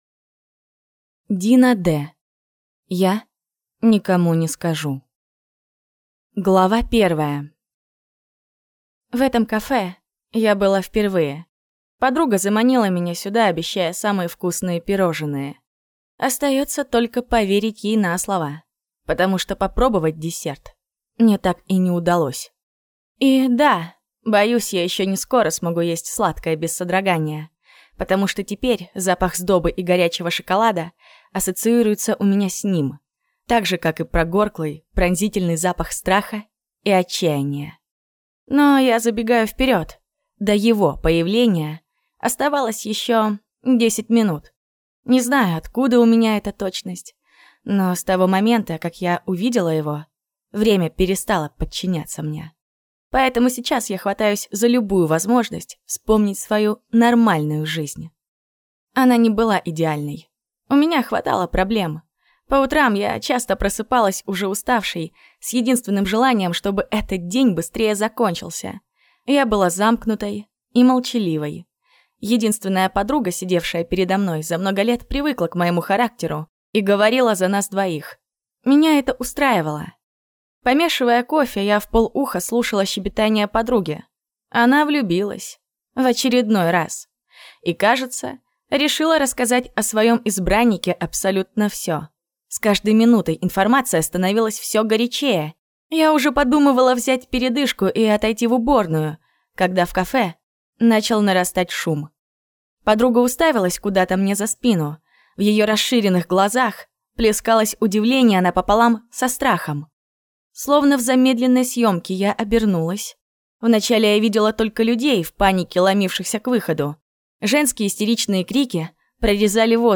Аудиокнига Я никому не скажу | Библиотека аудиокниг
Прослушать и бесплатно скачать фрагмент аудиокниги